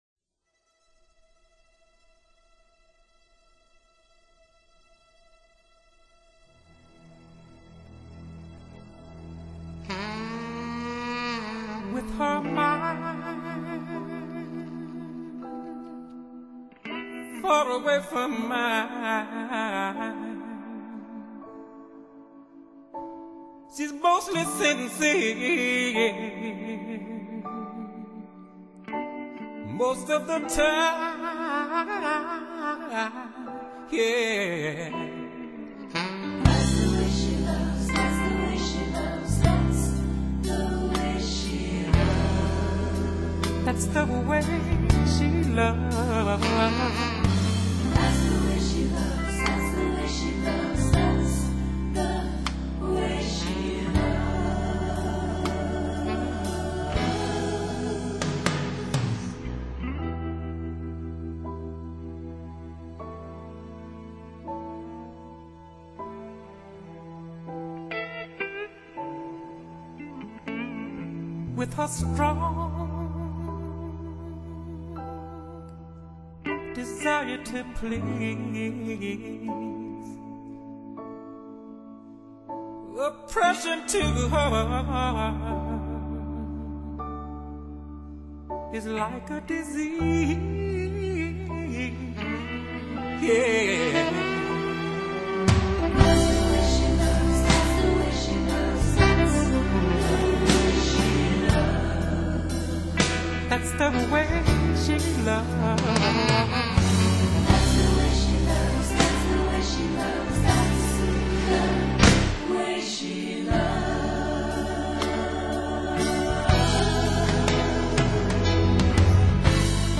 高歌时那把浑然天成的嗓子，听似漫不经心，却令人神往
全为一流抒情歌曲。录音勿须怀疑，暖暖的音色之中，乐器结像定位清晰